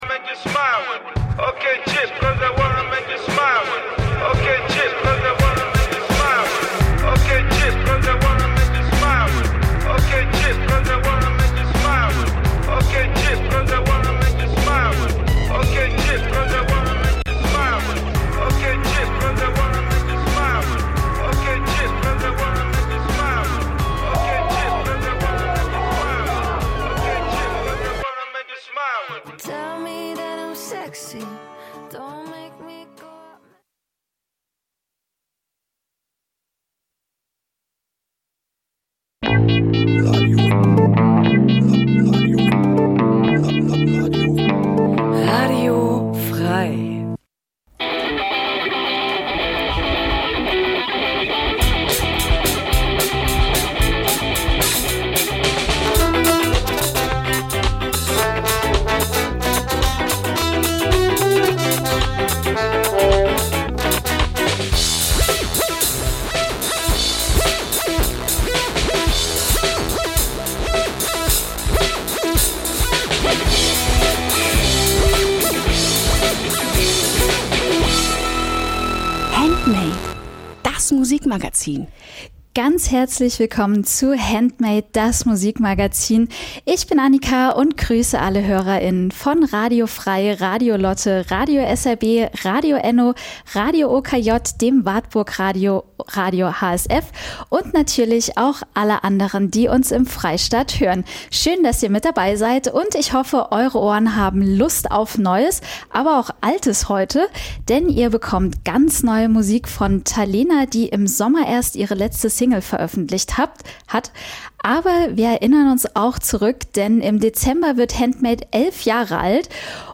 Jeden Donnerstag stellen wir euch regionale Musik vor und scheren uns dabei nicht um Genregrenzen.
Wir laden Bands live ins Studio von Radio F.R.E.I. ein, treffen sie bei Homesessions oder auf Festivals.